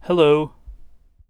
hello.wav